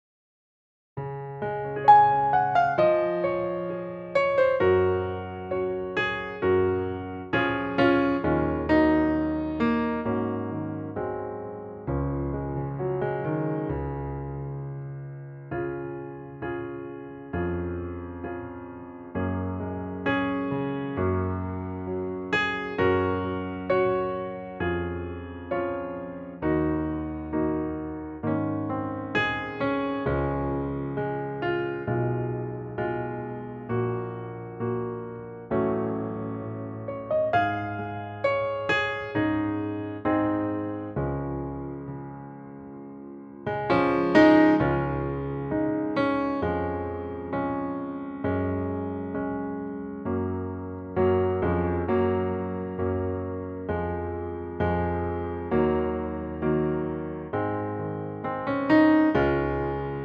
Unique Backing Tracks
key - Db - vocal range - Ab to Db (Eb falsetto)
just piano and vocal, same arrangement and key.